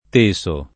teso [ t %S o ]